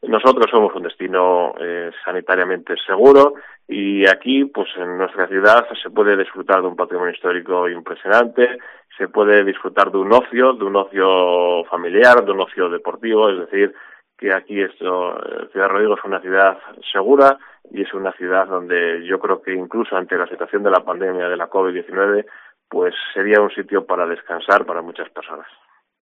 El alcalde de Ciudad Rodrigo, Marcos Álvarez, destaca sobre lo que ofrece Ciudad Rodrigo al visitante